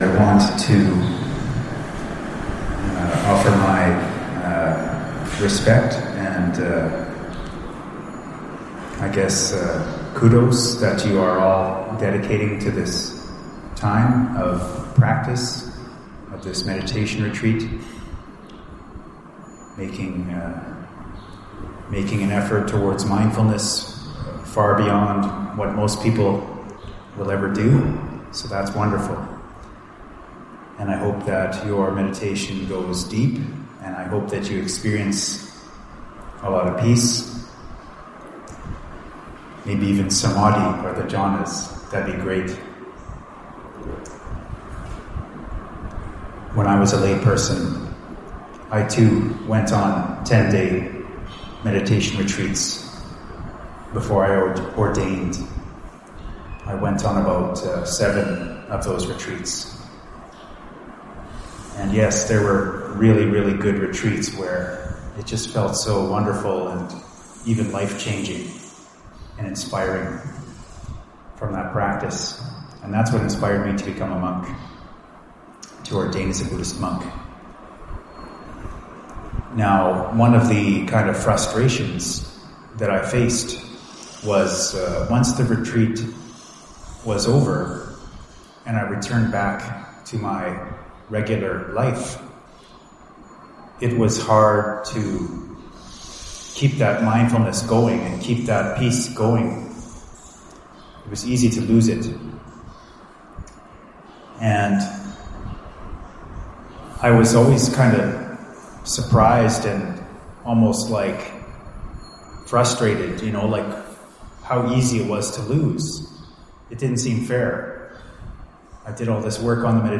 Here is a 7-min recorded audio-only Dhamma Talk, recorded at Brahmavihara monastery, Malacca, Malaysia - downloadable